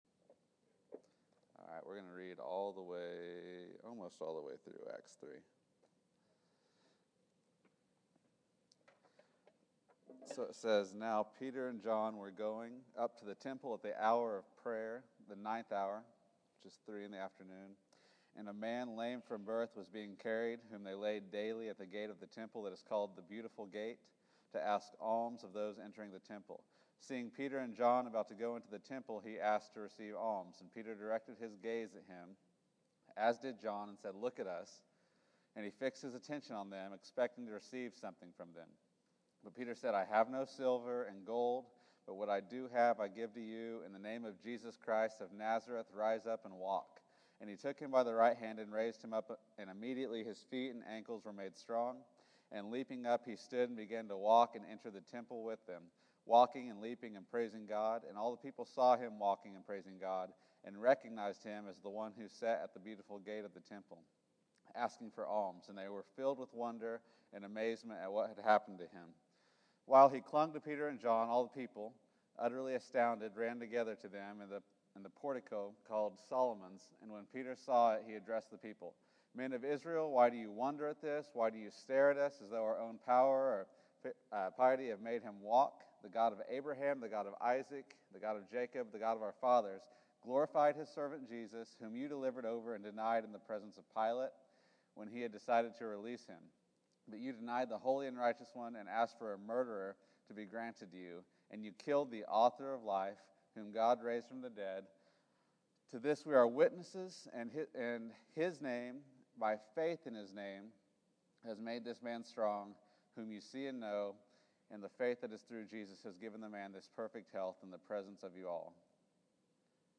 Acts 3 August 10, 2014 Category: Sunday School | Location: El Dorado Back to the Resource Library The name of Jesus is a gift from God given to us.